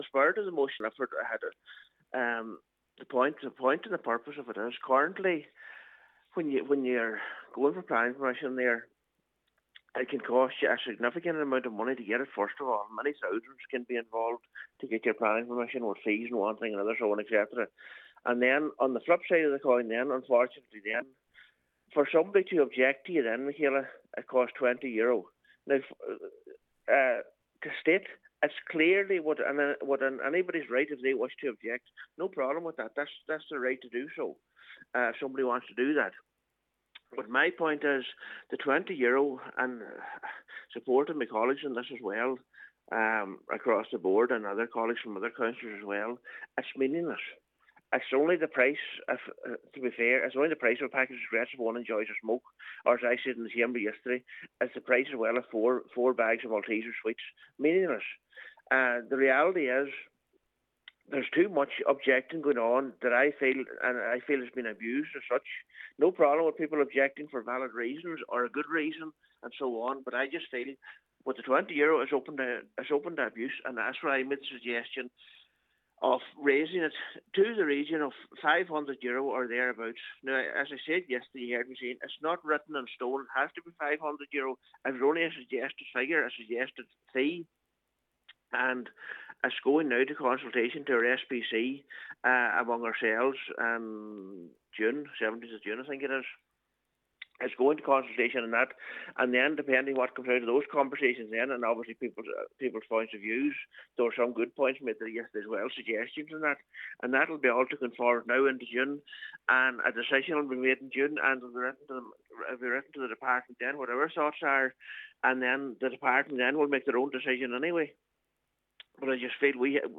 Councillor McClafferty says the process has become somewhat farcical: